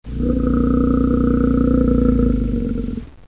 bear-groan-1.ogg